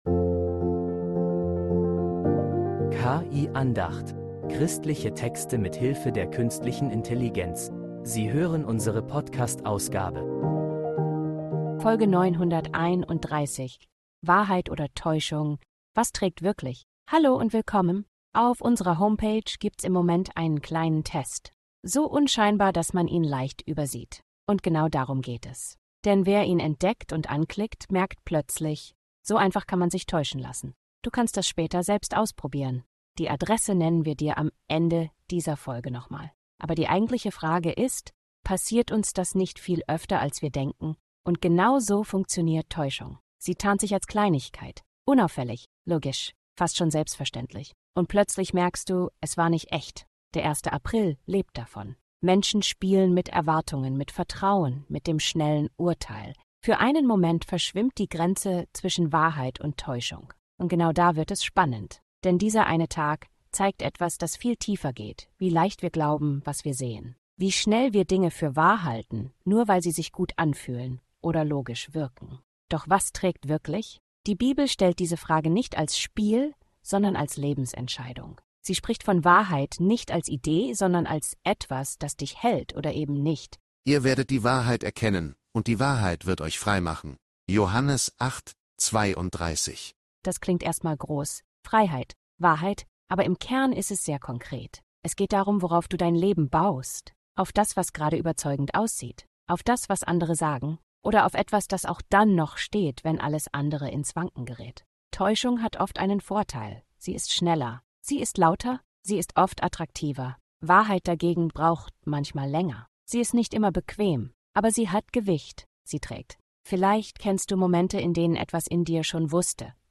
Diese Andacht stellt die Frage, was wirklich trägt, wenn alles